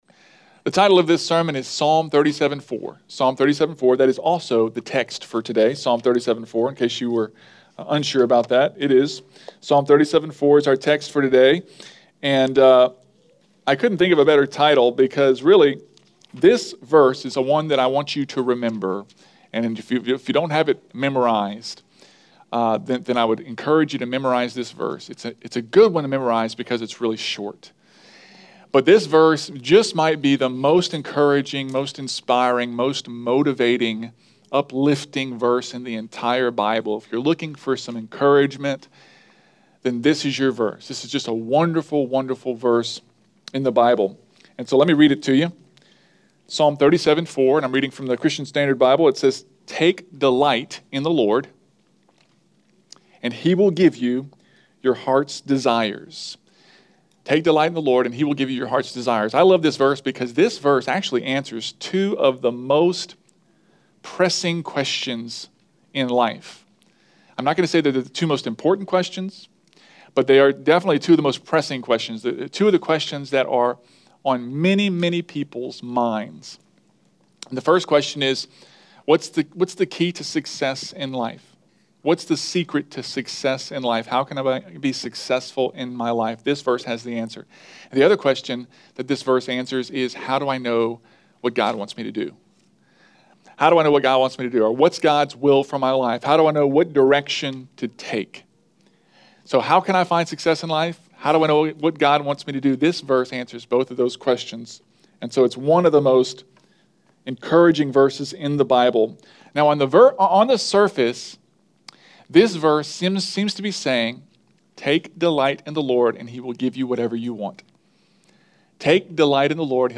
Stand-Alone Sermons